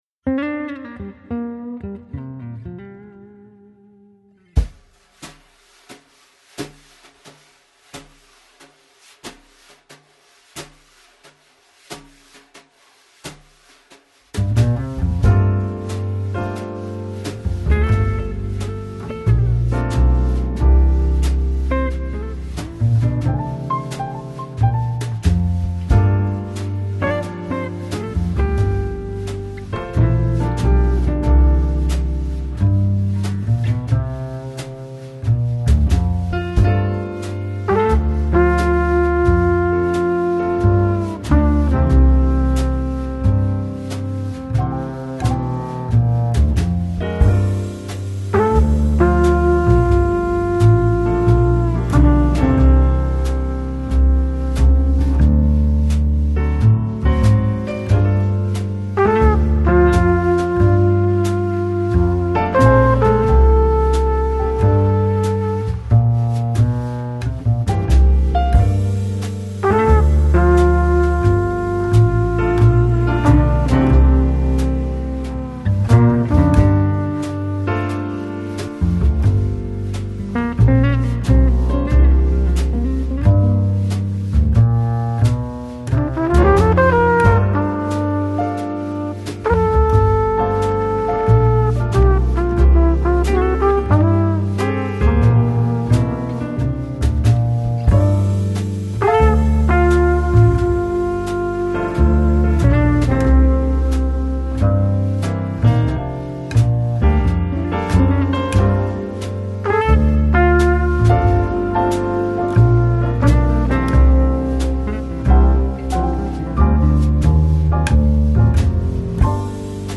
Жанр: Chillout, Jazz, Lounge